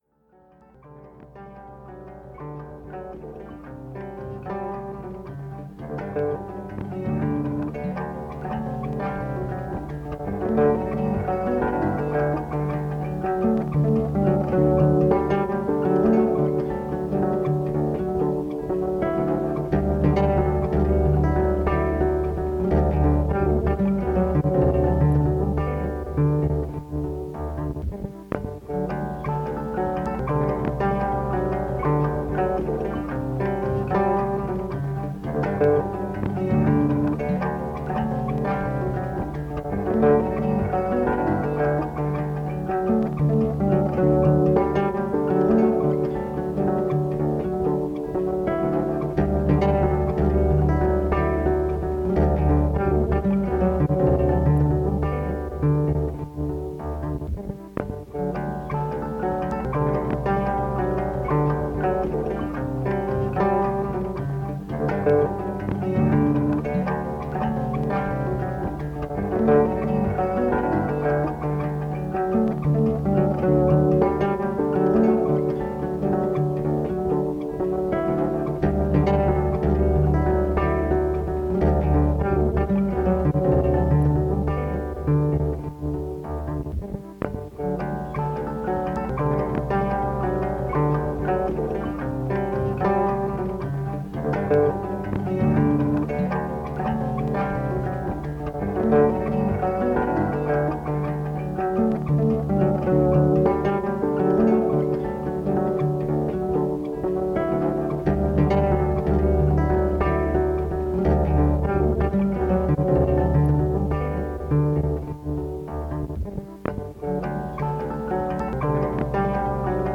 Instrumentation: guitar on looped cassette tapes
McEvoy Foundation for the Arts, San Francisco, CA.